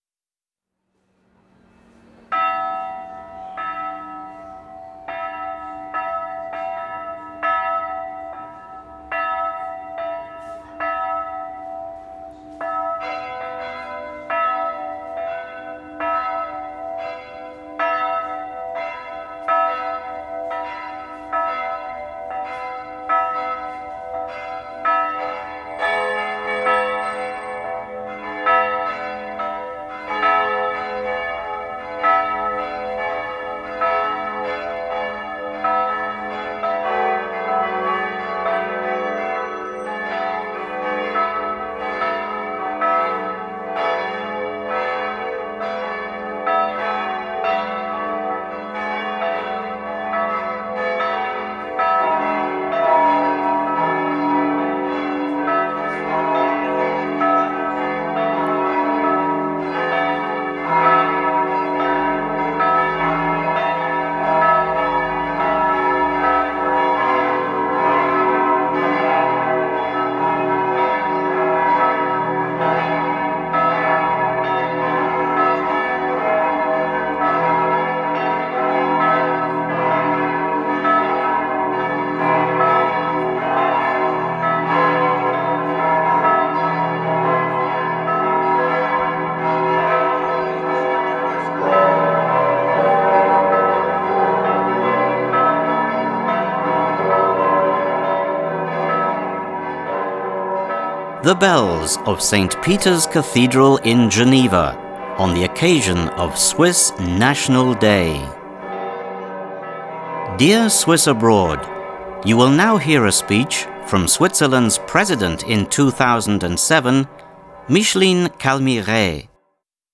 President Micheline Calmy-Rey's speech to the Swiss abroad on the Swiss national day.